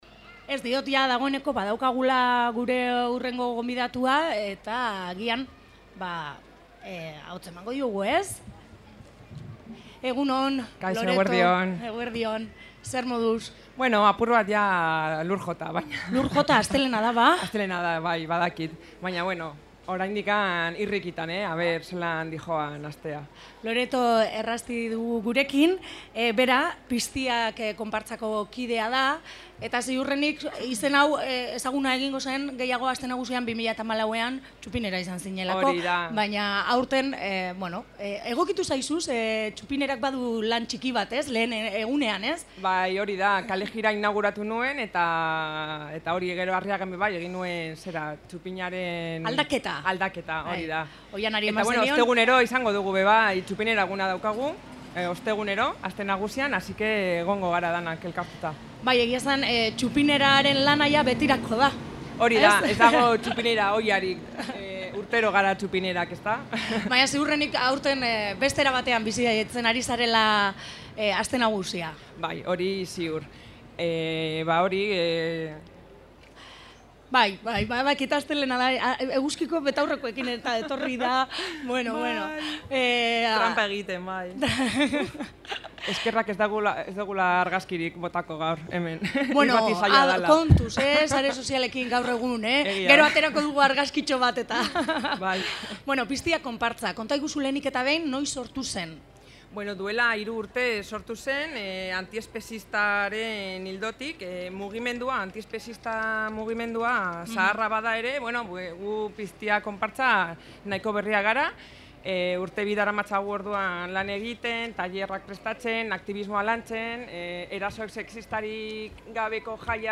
elkarrizketan